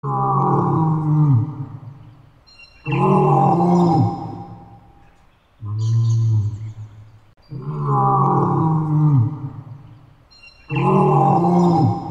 Klingelton Löwin
Kategorien Tierstimmen